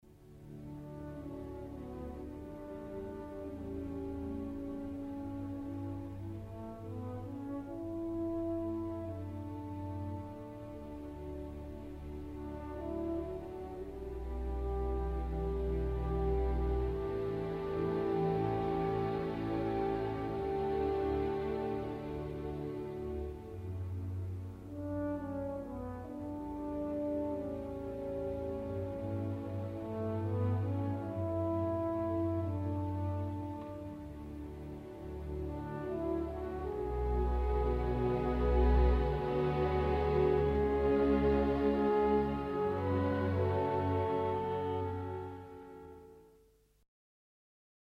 Il moderno corno ha tre pistoni, un canneggio circolare di ottone che termina da un lato con un'ambia svasatura a campana e dall'altro con un bocchino ad imbuto che dà al corno il suo caratteristico timbro soffice e vellutato.
corno solo in orchestra
corno_solo.mp3